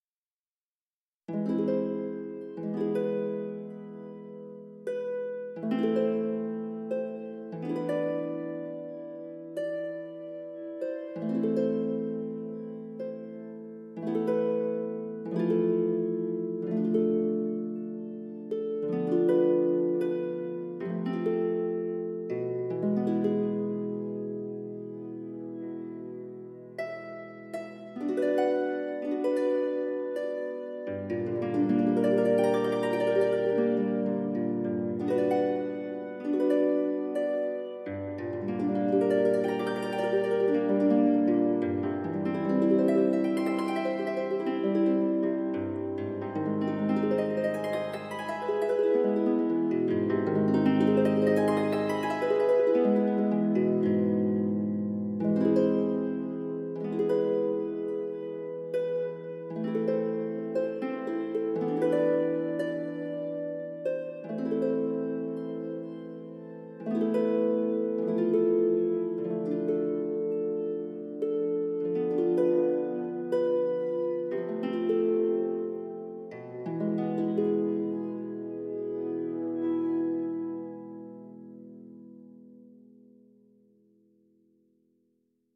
3. 管弦乐
Sonuscore Celestial Harp 是一种基于 Kontakt 的音色库，它提供了一种凯尔特竖琴的声音，可以通过 GLOW 引擎将传统的拨弦声转变为全方位的空灵垫音。
CELESTIAL HARP是一把凯尔特竖琴，具有神圣灵感的声音，具有独特的音色，弹拨温暖柔和，但郁郁葱葱，充满饱满的弹奏。它非常适合将您的听众带入幻想世界和古代环境，尤其是与我们的 Glow 滑块的粒子力量配对时，它将声音转化为令人难以置信的、舒缓的和通风的打击垫。
用 5 个循环记录，它还包括创建谐波层的鞭毛技术。